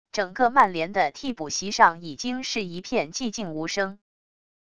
整个曼联的替补席上已经是一片寂静无声wav音频生成系统WAV Audio Player